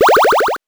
powerup_16.wav